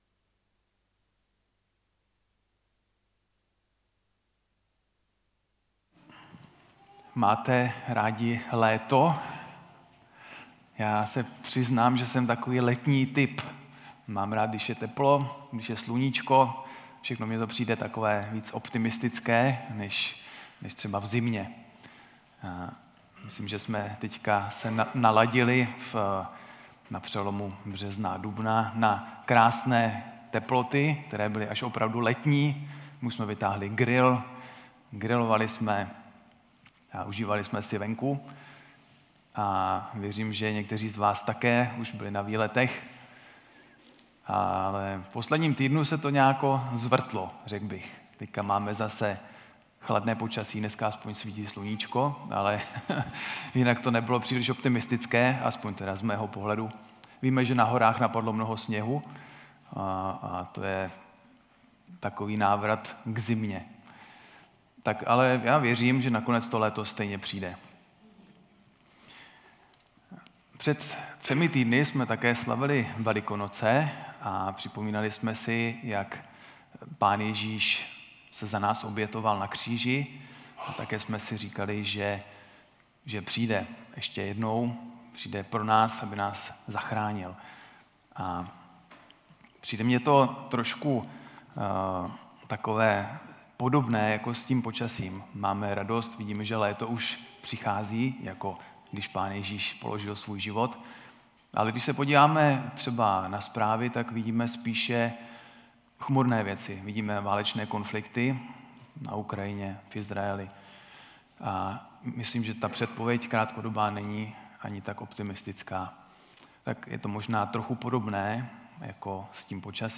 Ztráty - nedělní bohoslužba
Kategorie: Nedělní bohoslužby